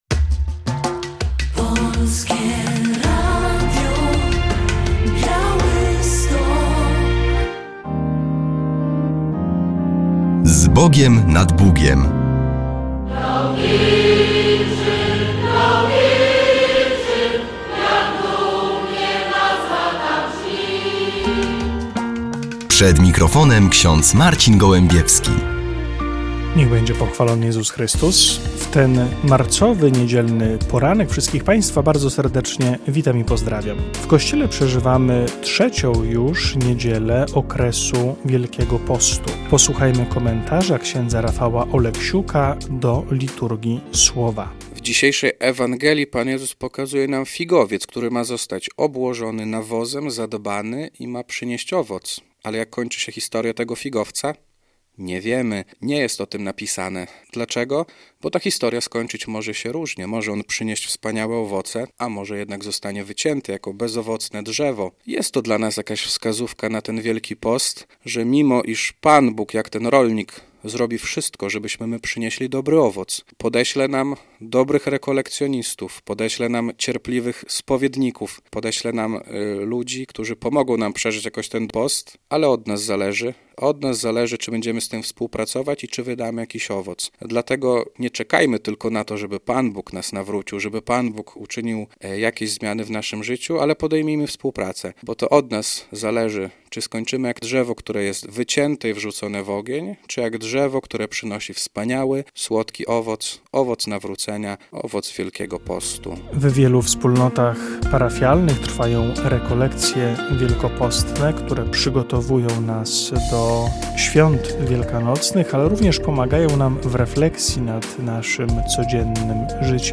W audycji relacja z pogrzebu